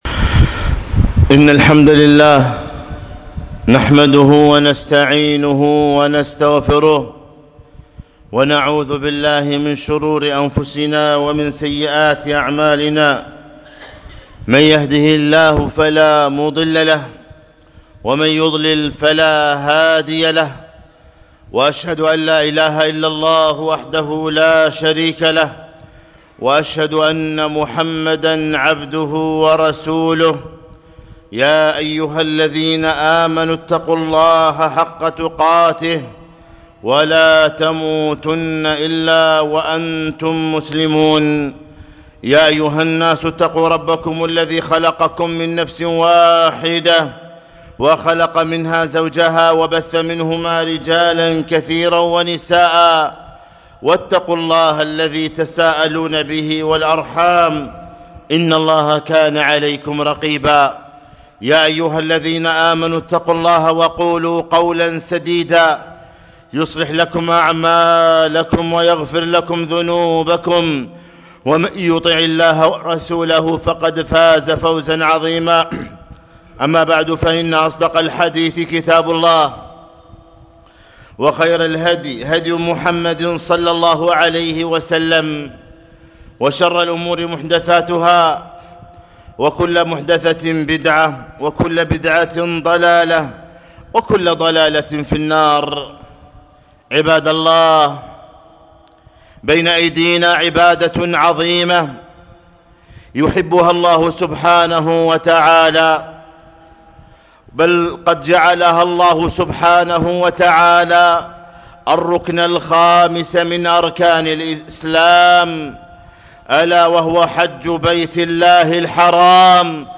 خطبة - تعجلوا إلى الحج